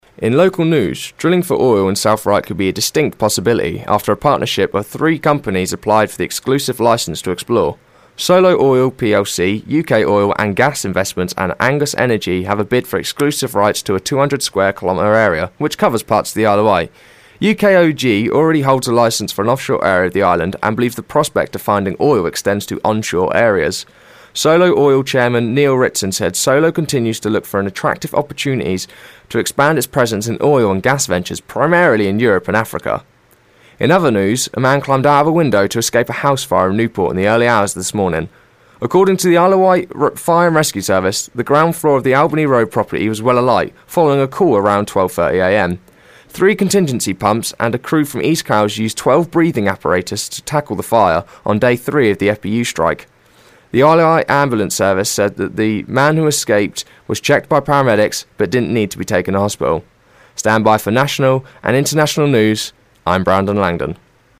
First News Bulletin